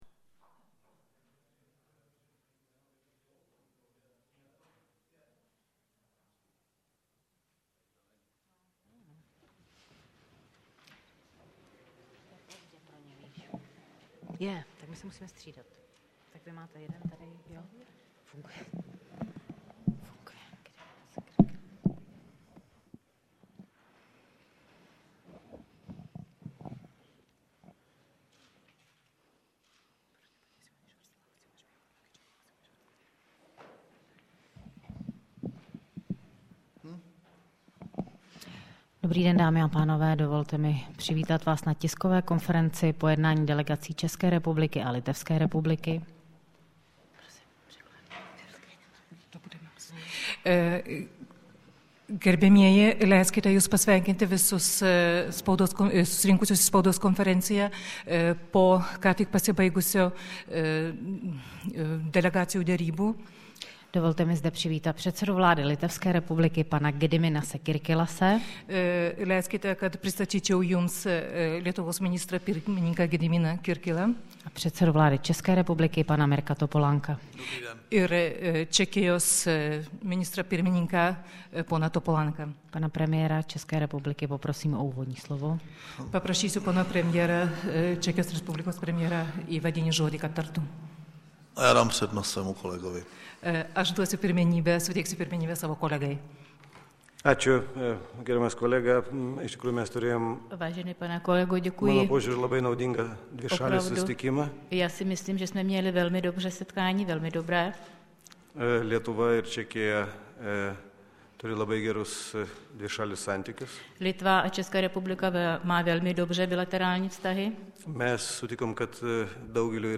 Tisková konference po jednání premiéra Mirka Topolánka s předsedou vlády Litevské republiky Gediminasem Kirkilasem 18.3.2008
tk_litva_18.3.08.mp3